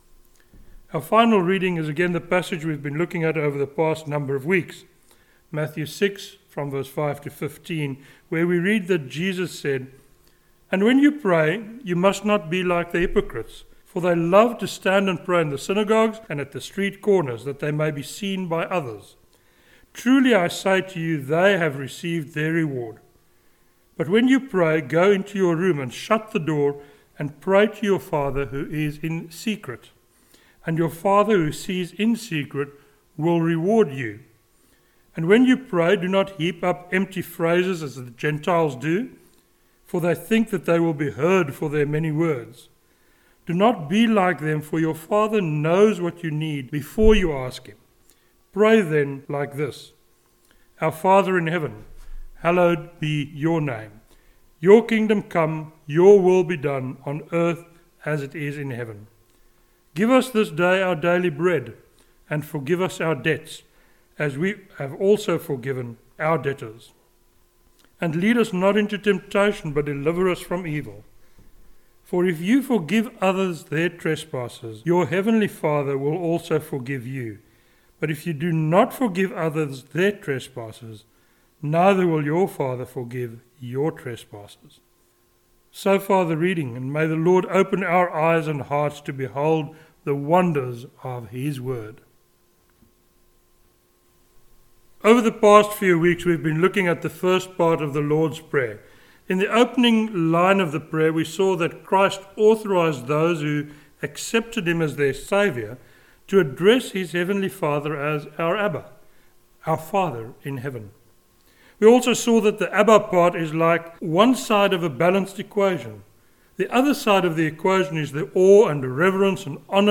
(The fourth sermon in a series on the Lord’s Prayer.)